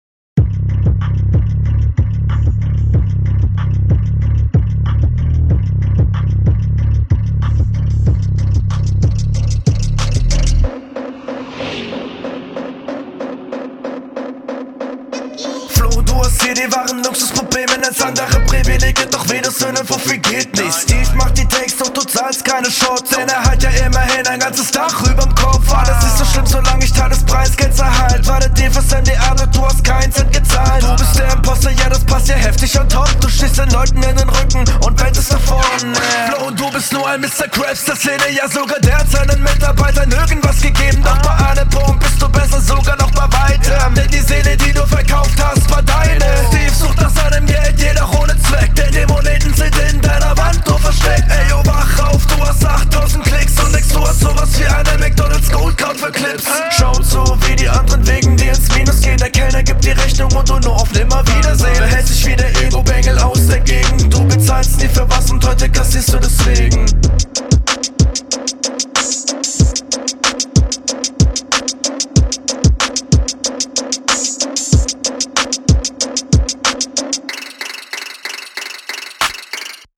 Der Flow ist leicht unterlegen, aber insgesamt ist das ebenfalls ein tolles Konzept.